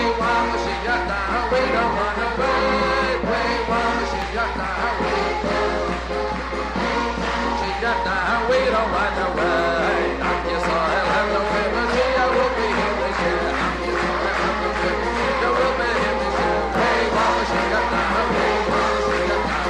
niggun-we-want-moshiach-now.mp3